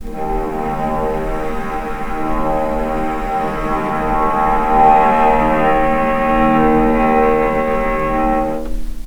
healing-soundscapes/Sound Banks/HSS_OP_Pack/Strings/cello/sul-ponticello/vc_sp-D#2-pp.AIF at 61d9fc336c23f962a4879a825ef13e8dd23a4d25
vc_sp-D#2-pp.AIF